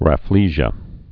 (ră-flēzhə)